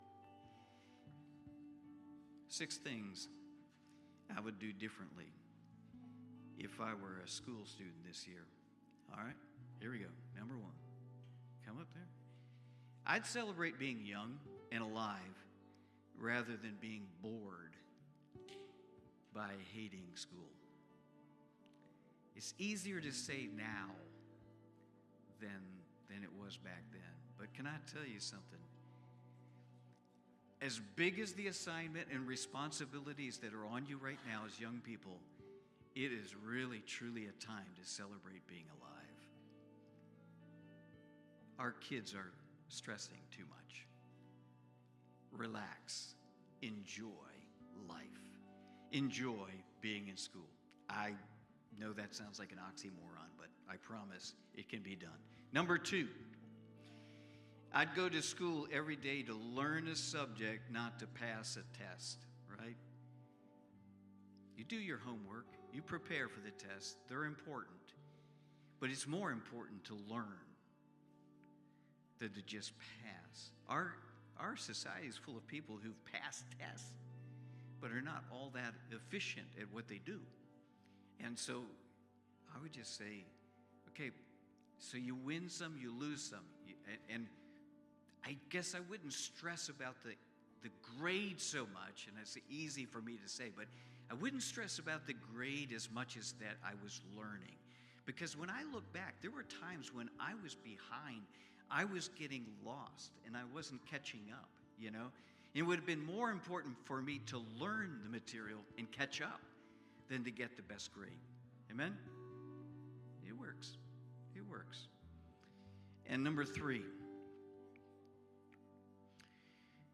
Children Watch Listen Save Cornerstone Fellowship Sunday morning service, livestreamed from Wormleysburg, PA.